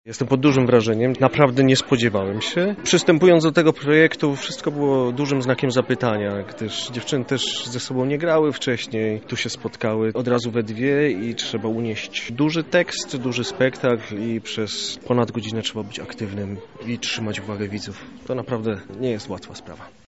Po spektaklu zapytaliśmy o wrażenia